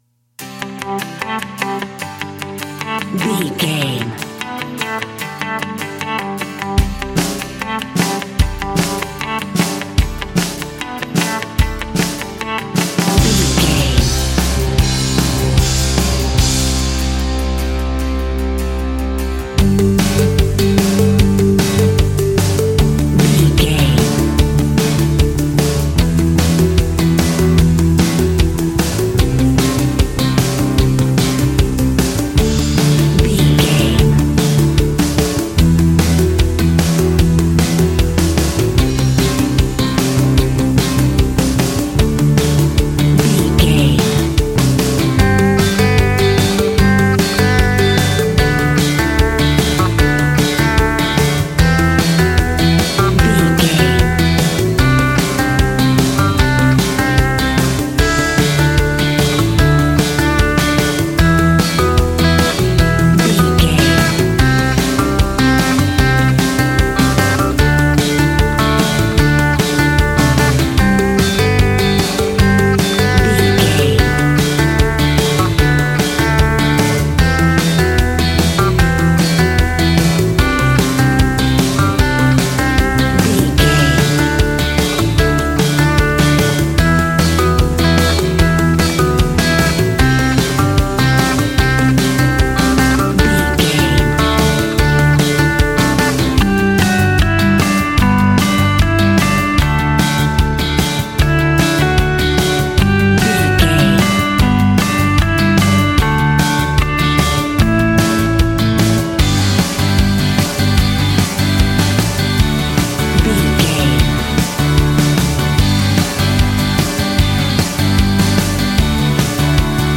Ionian/Major
D
groovy
powerful
organ
bass guitar
electric guitar
piano